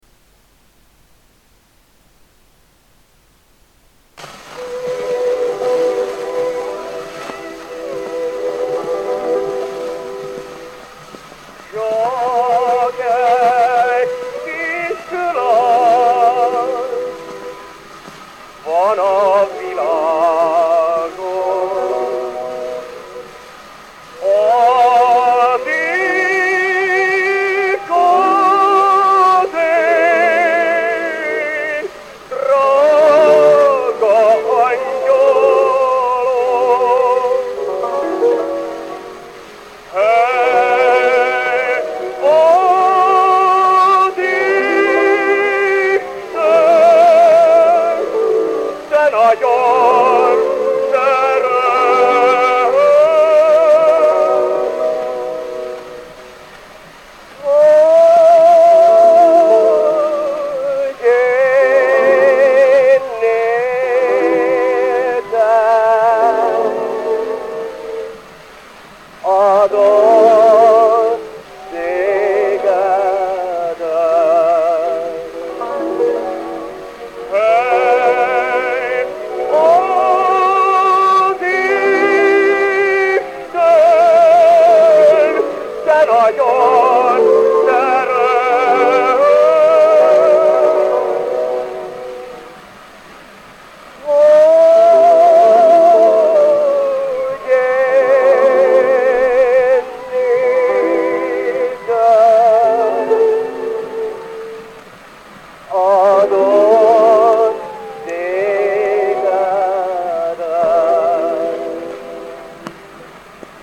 Обнаружилась одна из ранних звукозаписей широко известной в первой половине ХХ столетия мадьярской "ноты" Элемера Сентирмаи "В мире есть красавица одна".
В 1910 году её записал (в сопровождении цыганского оркестра
тенор